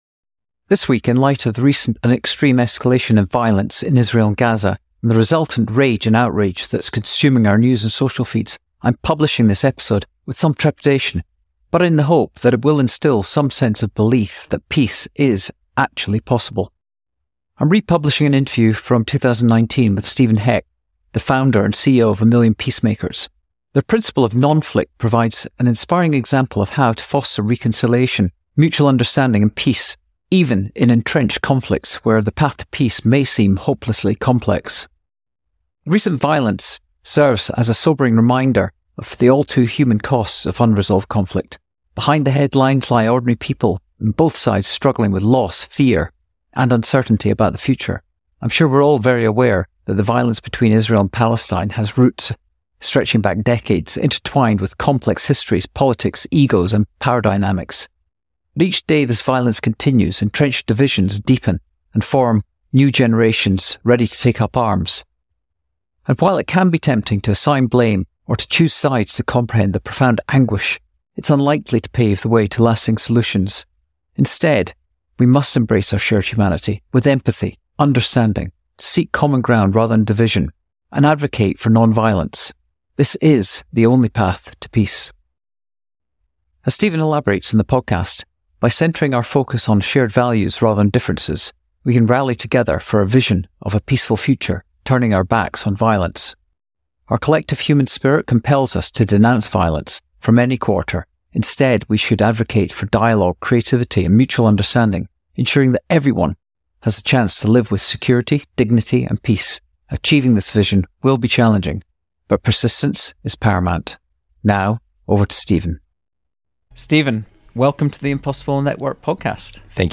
Nonflict - republishing an interview